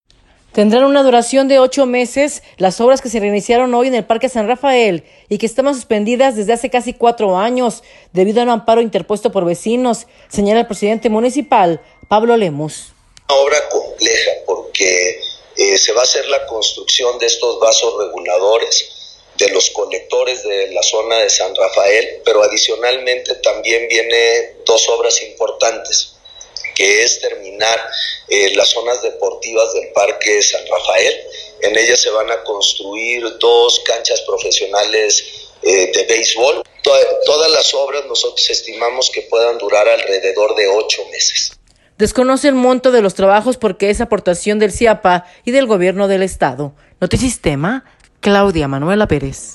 Tendrán una duración de ocho meses las obras que se reiniciaron hoy en el Parque San Rafael y que estaban suspendidas desde hace casi cuatro años debido a un amparo interpuesto por vecinos, señala el presidente municipal, Pablo Lemus.